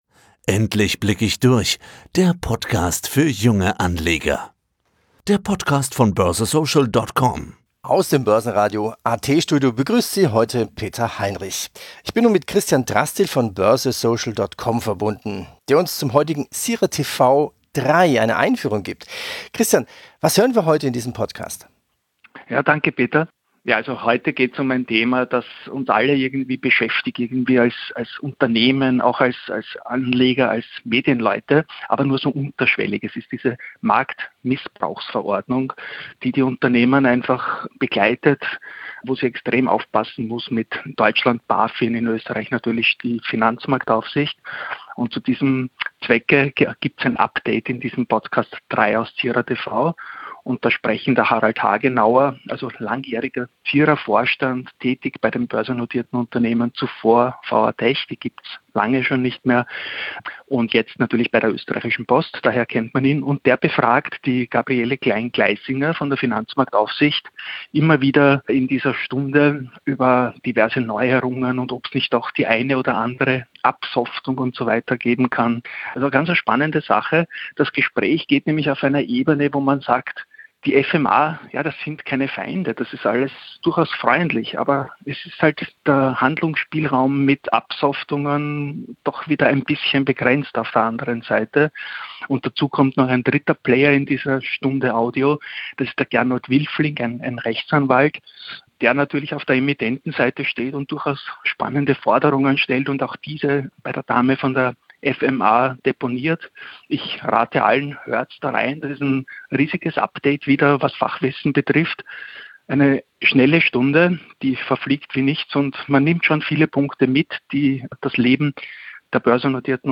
Teil 3 der anmoderierten Audio-Spur von CIRA-TV auf unserem Podcast: Diesmal geht es um ein Update zur MAR (Market Abuse Regulation, Marktmissbrauchsverordnung).